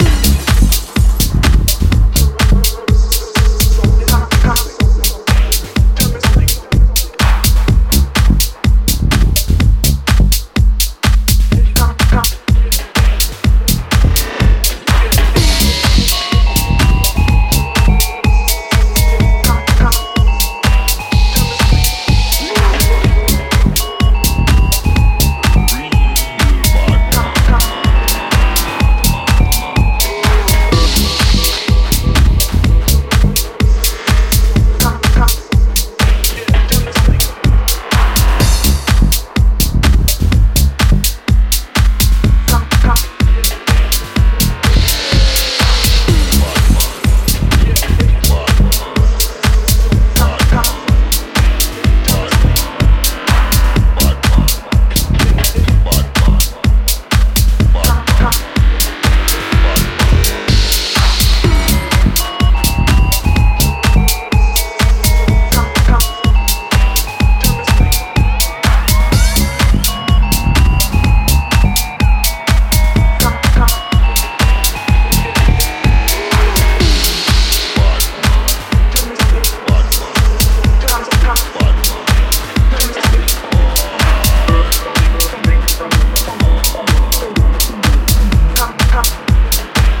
際立ってパワフルな内容に仕上がっています！